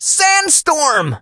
sandy_ulti_vo_02.ogg